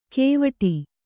♪ kēvaṭi